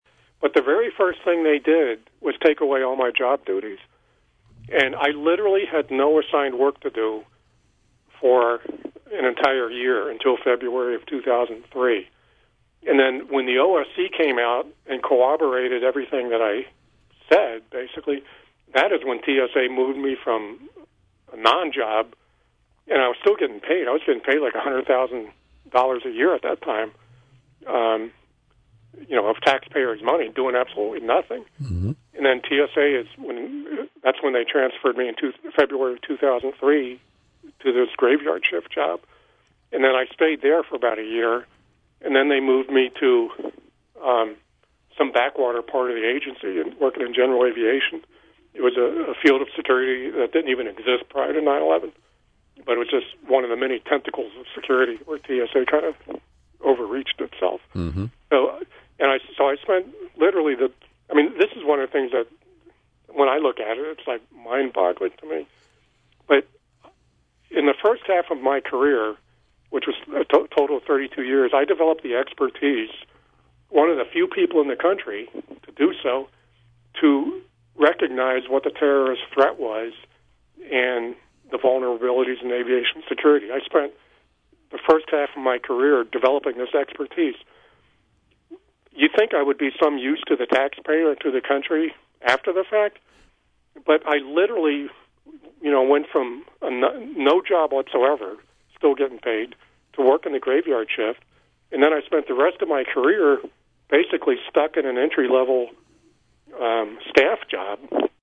In-Depth Interview